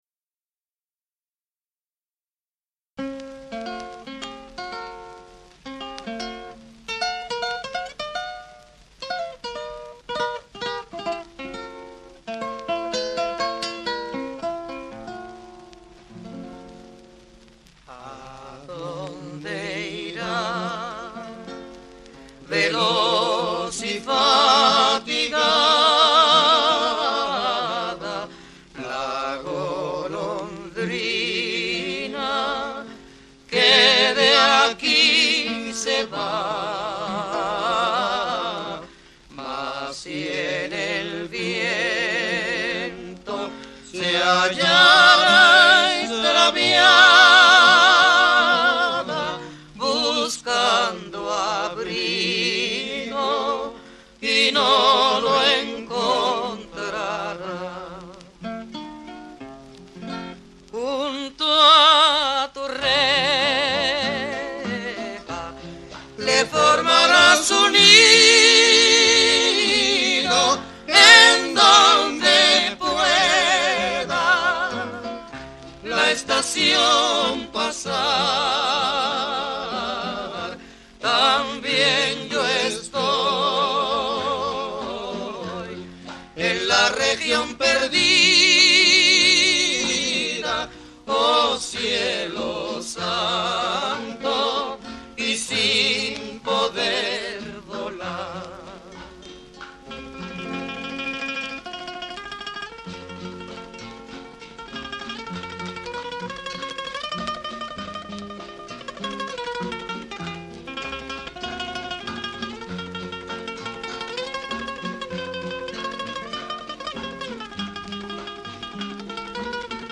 High Fidelity recording